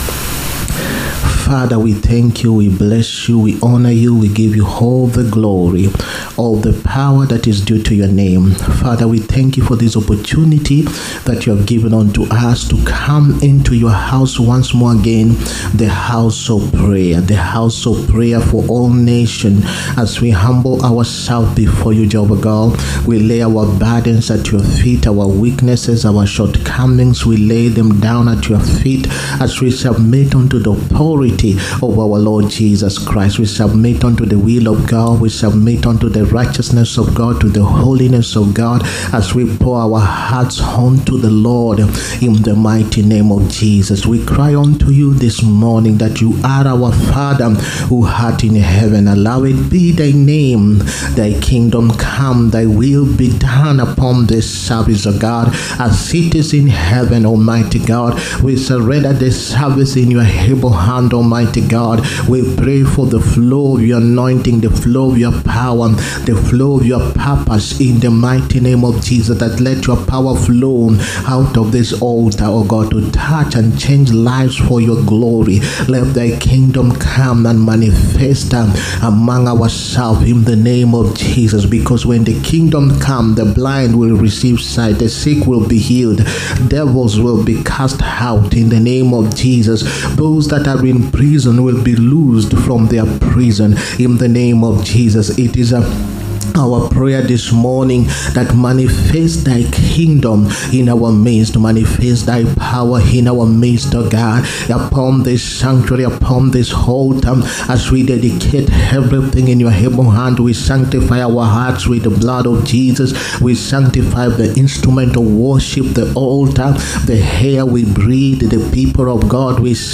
SUNDAY BLESSING SERVICE. THE GRACE OF GIVING. 9TH MARCH 2025.